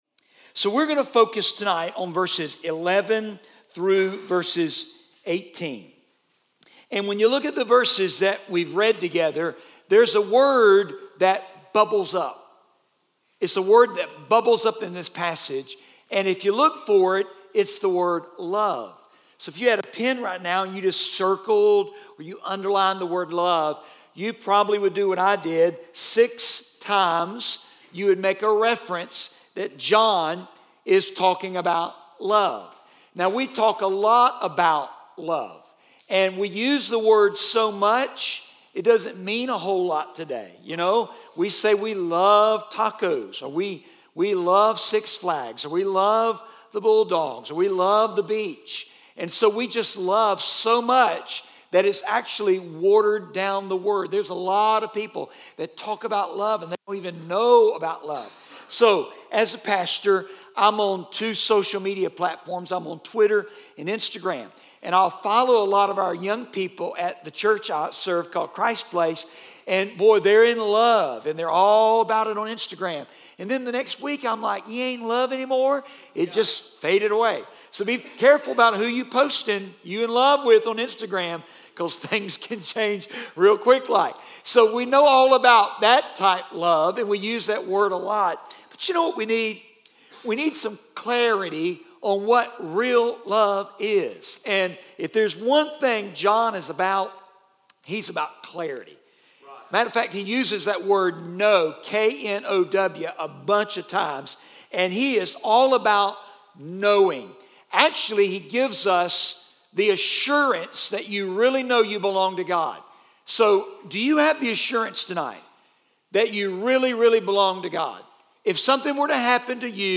Message #06 from the ESM Summer Camp sermon series through the book of First John entitled "You Can Know"